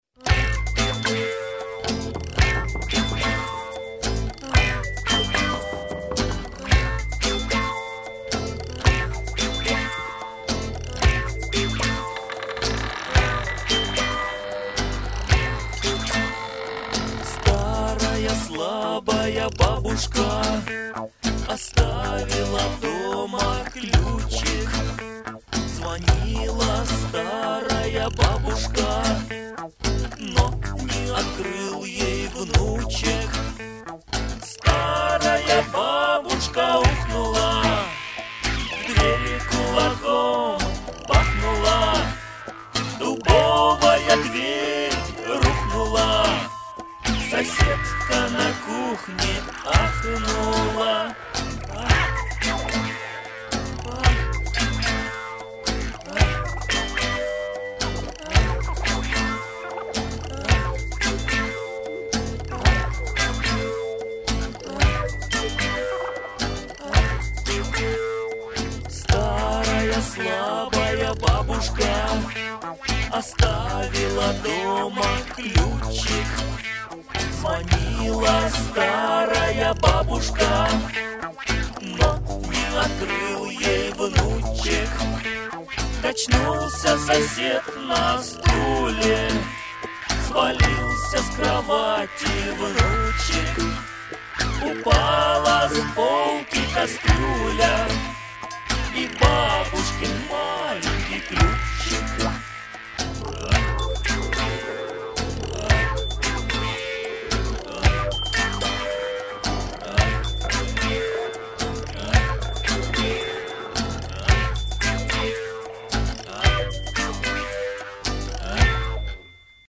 🎶 Детские песни / Песни про бабушку